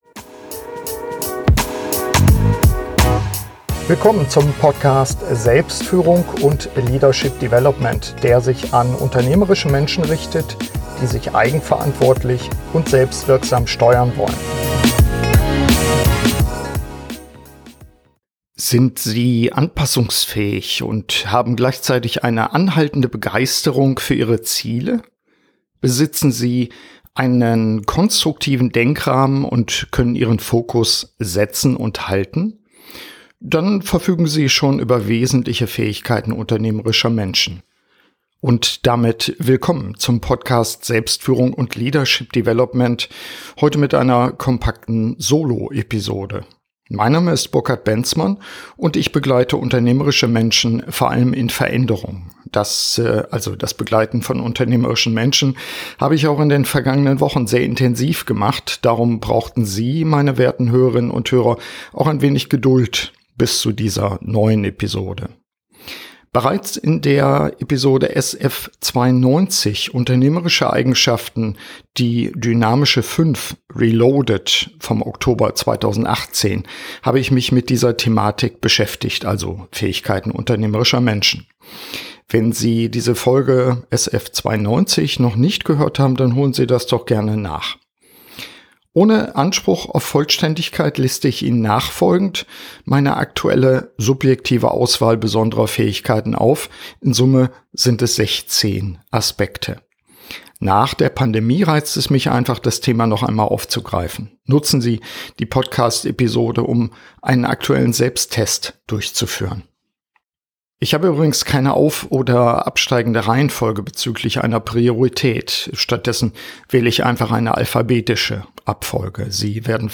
In dieser Solo-Episode meines Podcasts wähle ich 16 Fähigkeiten aus, die nach meiner Erfahrung wesentlich sind für erfolgreiche unternehmerische Menschen. Sie reichen von A wie Anpassungsfähigkeit über E wie Eigenmotivation bis W wie Werte.